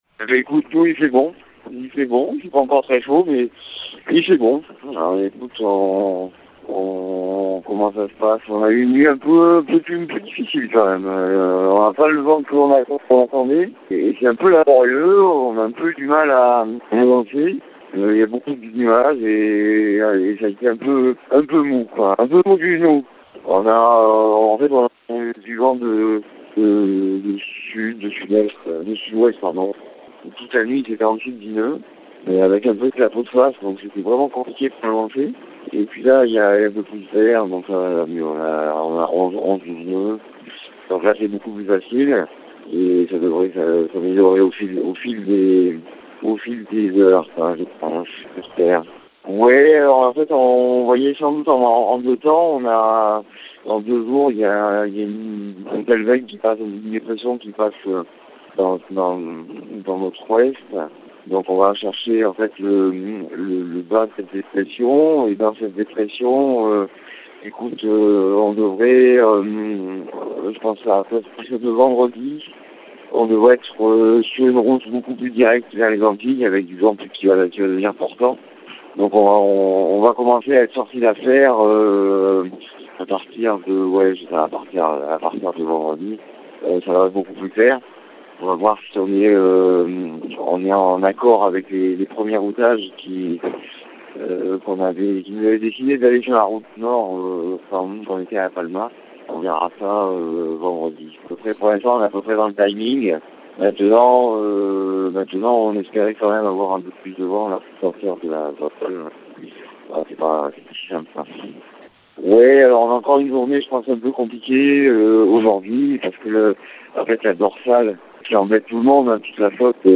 Kito de Pavant, joint par son équipe ce jeudi matin.